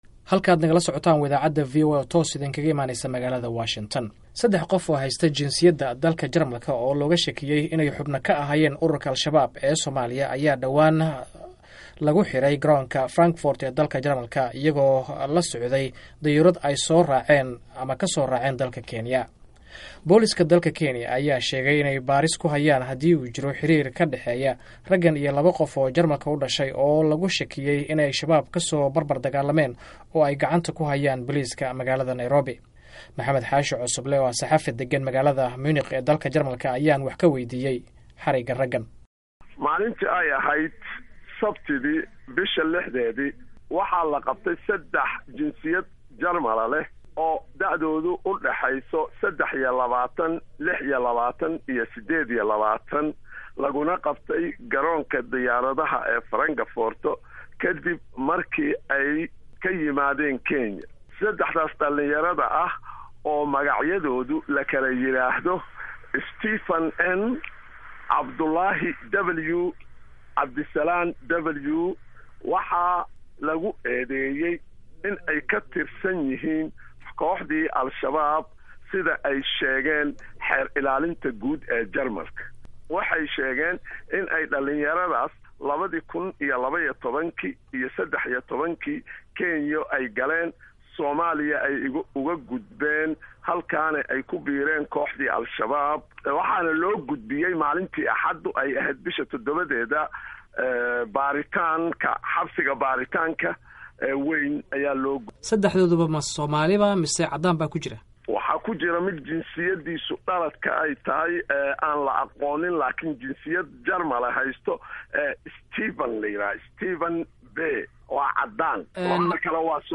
Dhageyso Wareysiga la Xiriira Rag Lagu Qabtay Jamalka